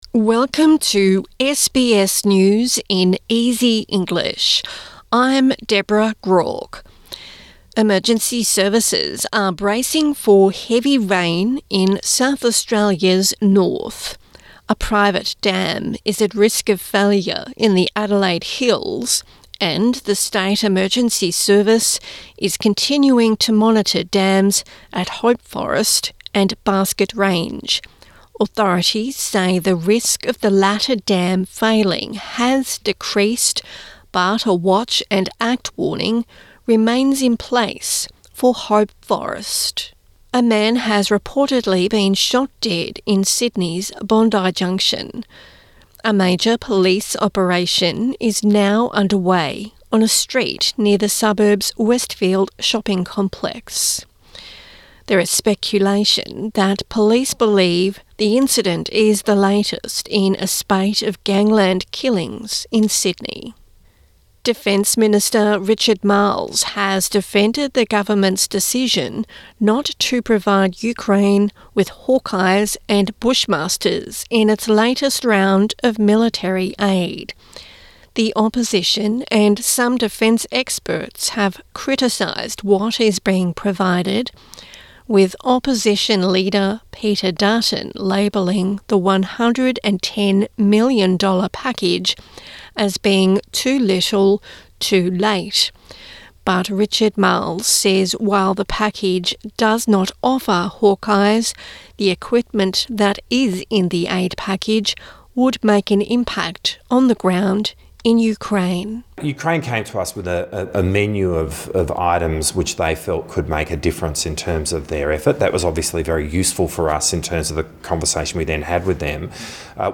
A daily 5 minute news wrap for English learners and people with disability